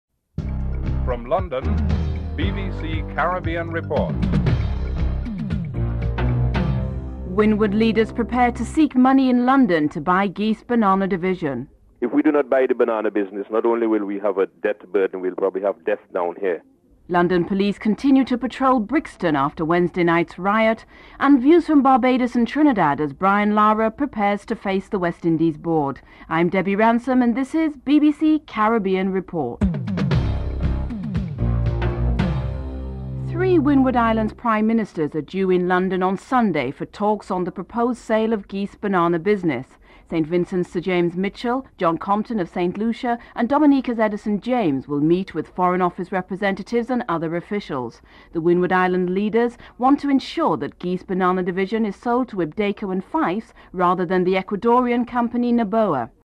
In this report Windward Islands leaders, Sir James Mitchell of St. Vincent, John Compton of St. Lucia and Dominica's Edison James prepare to seek funding in London to purchase the banana division of Geest. Prime Minister Edison James comments on whether the British government can offer any assistance and he also discusses the effect this debt can be a burden on the Windward Islands.